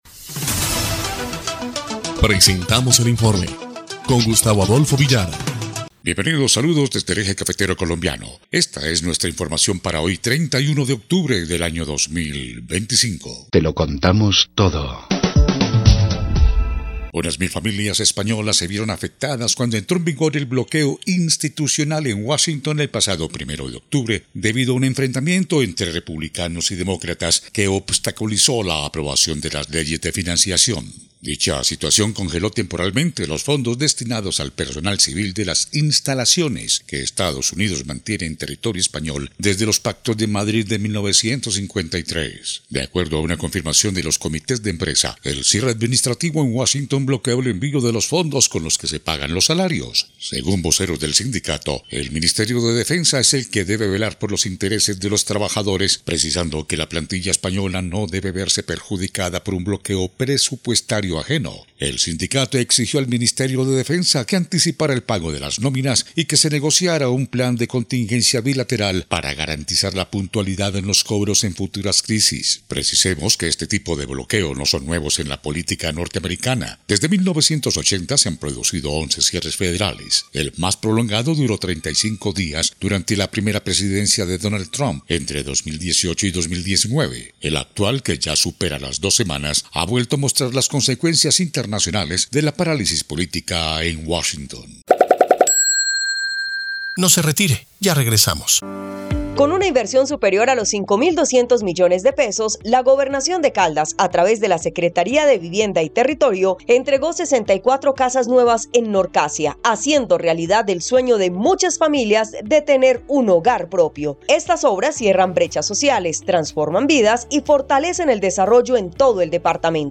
EL INFORME 2° Clip de Noticias del 31 de octubre de 2025